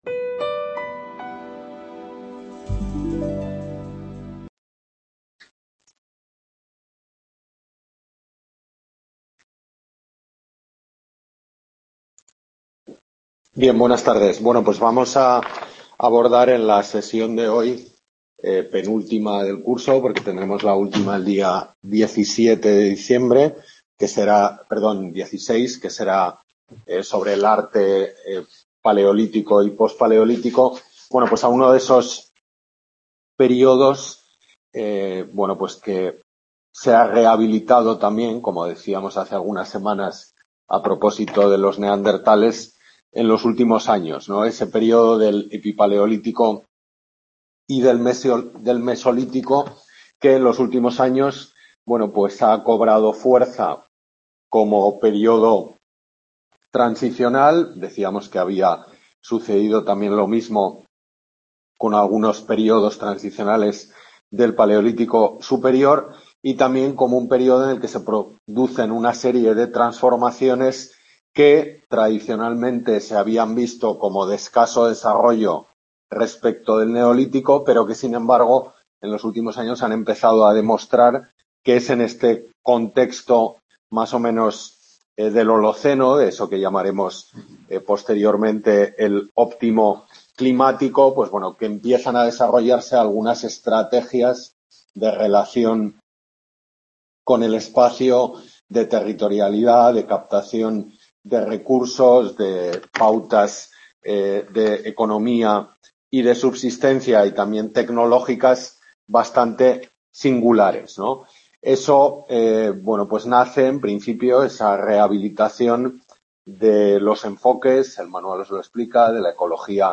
Tutoría Prehistoria Antigua de la Península Ibérica